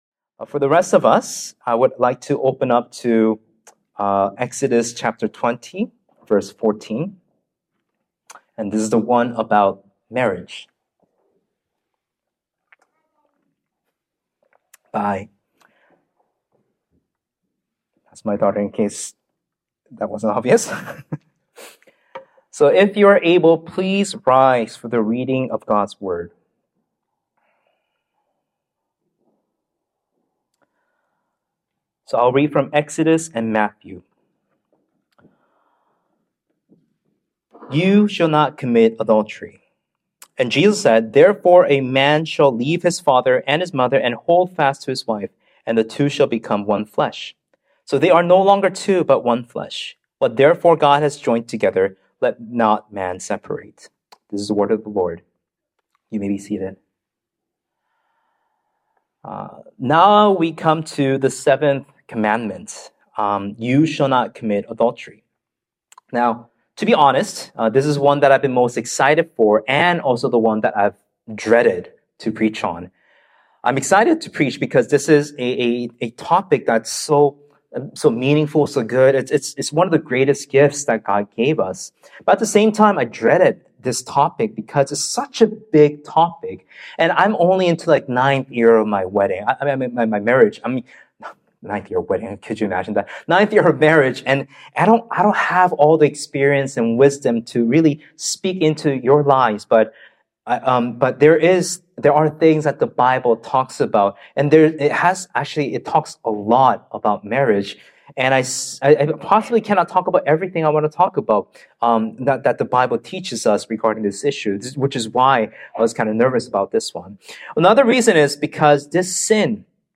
Sermons | Sonflower Community Church